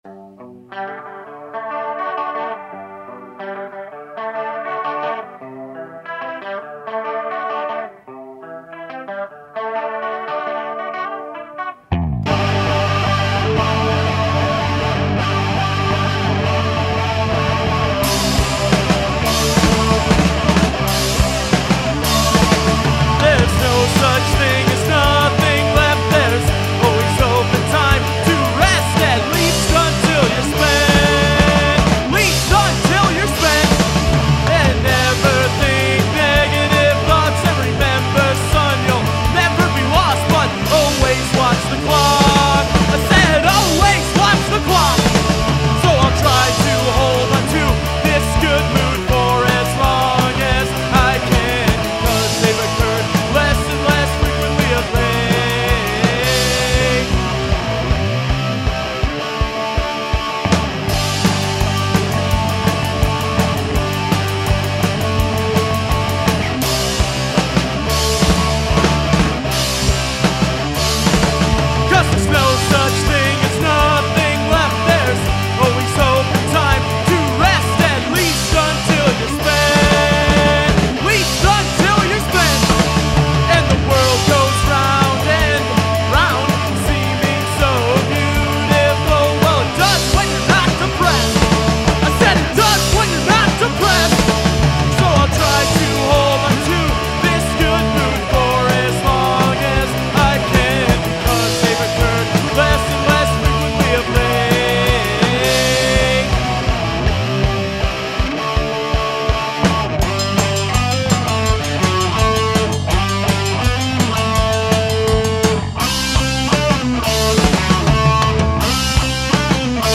Music Style: Punk